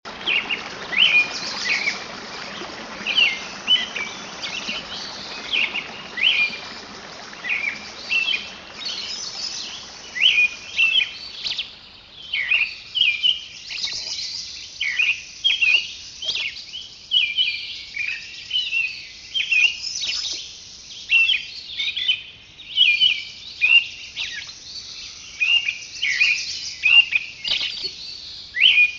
Animals sounds